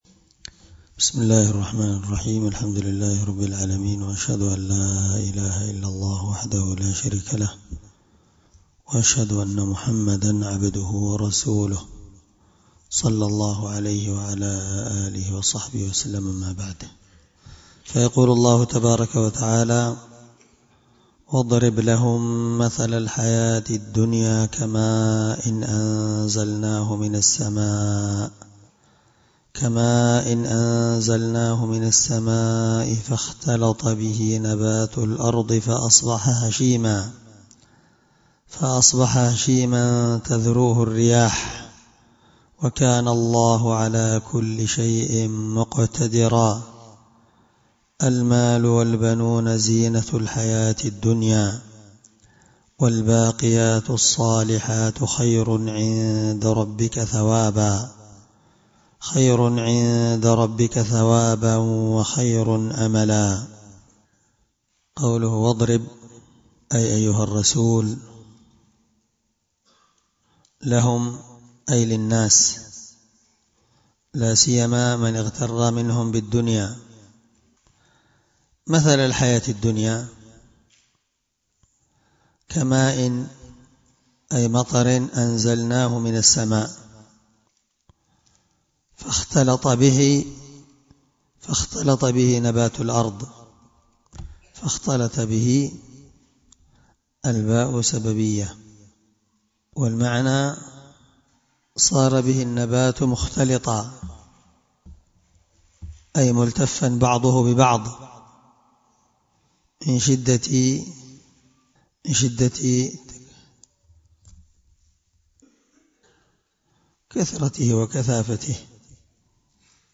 الدرس16 تفسير آية (45-46) من سورة الكهف